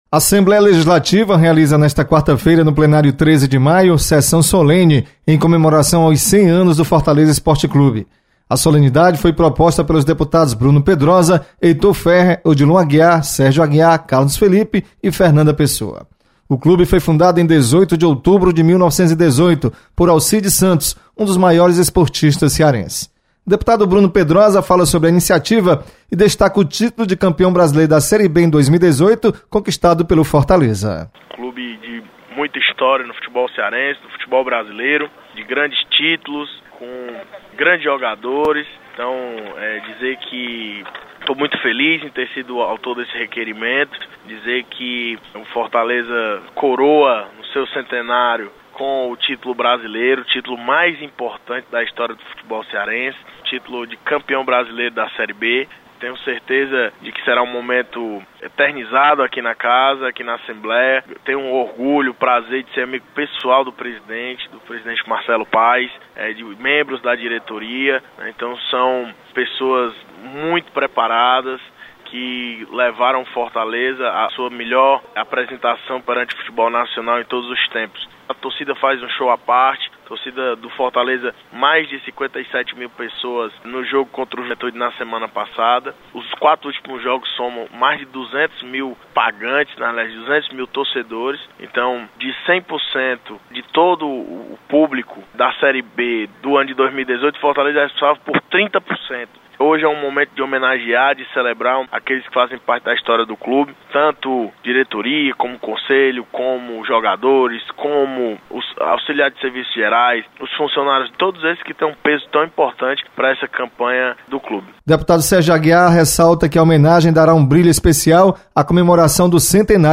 Assembleia Legislativa realiza sessão Solene em homenagem aos 100 anos do Fortaleza Esporte Clube. Repórter (3'02").